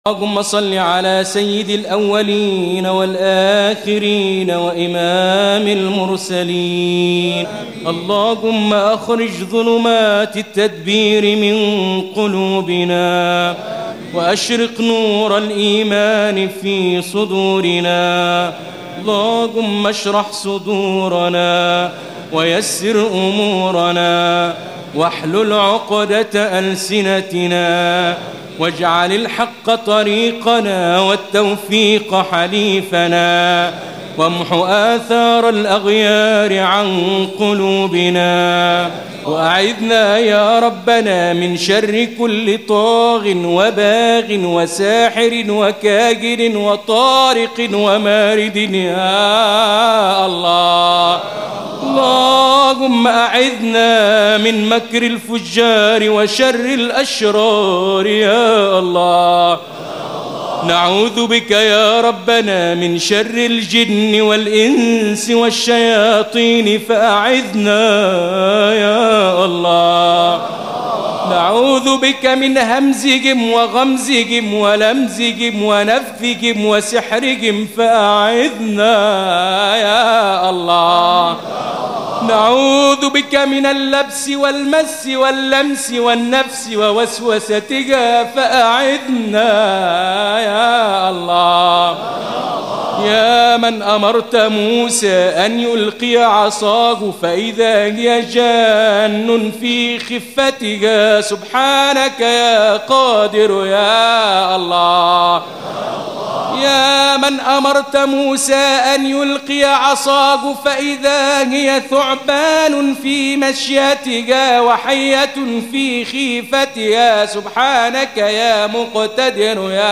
القارئ الطبيب صلاح الجمل، أحد كبار أعلام مدرسة التلاوة المصرية، حفظ القرآن الكريم، وعمره 10 سنوات وحصل على المركز الأول على مستوى الدقهلية، ثم الثانى على الجمهورية، وعمره 11 عاماً، حتى وصل إلى العالمية ليحصل على المركز الأول فى مسابقة كيب تاون بجنوب أفريقيا عام 1995، شاء الله له أن يطوف العالم ممثلًا لمصر من قبل وزارة الأوقاف عام 1987 قارئاً للقرآن، إلى أن طلبته الإذاعة المصرية حتى اجتاز اختباراتها منذ أن بدأ رحلته مع القرآن، وبعد عرض برنامجه الشهير «دعاء الأنبياء»، الذى كان فاتحة الخير، احتل «الجمل» مكانة كبيرة فى قلوب المسلمين من شتى العالم، بسبب صوته العذب، الذى ما إن تسمعه حتى تقشعر الأبدان، ليجعلك تشعر بحلاوة القرآن وتستشعر آياته المحكمات، «الجمل» يرفض التقليد ويعيب على الكثيرين اتخاذهم لهم كمنهج فى تلاواتهم، ويطالب بالاهتمام بالكتاتيب وإعادة دورها، بالإضافة إلى النظر فى تشكيل لجنة اختيار القراء بالإذاعة، حتى تستعيد مصر ريادتها فى عالم التلاوة.